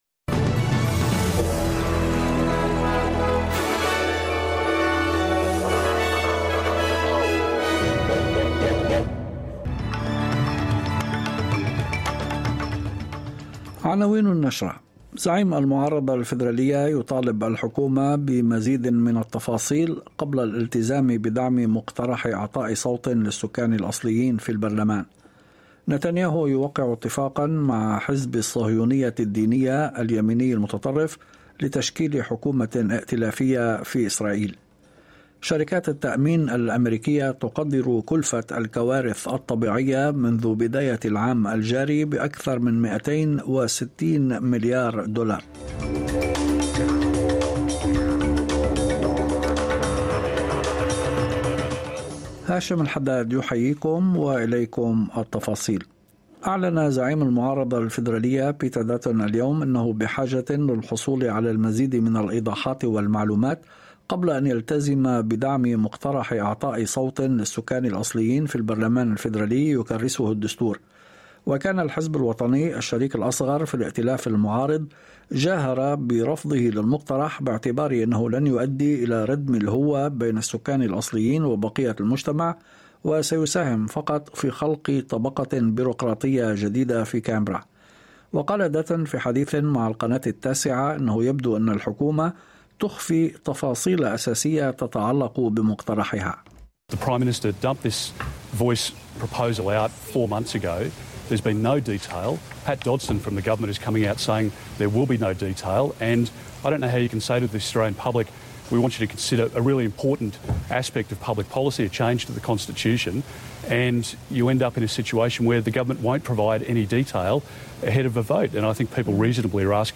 نشرة أخبار المساء 02/12/2022